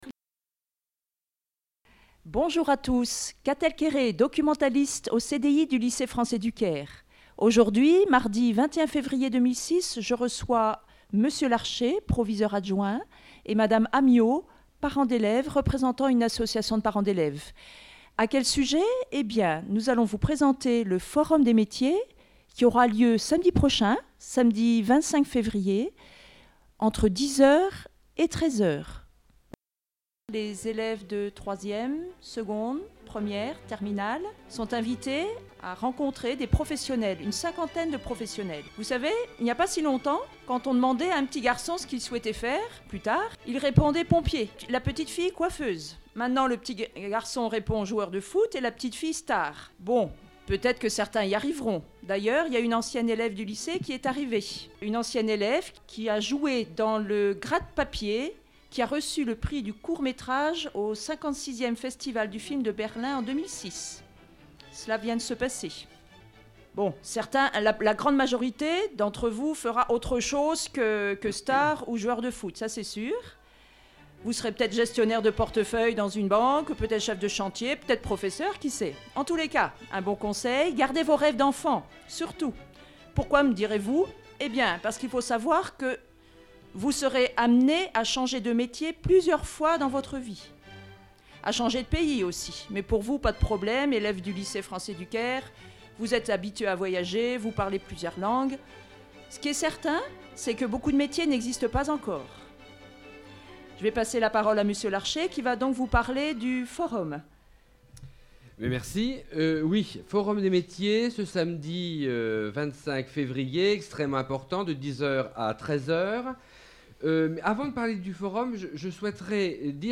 au studio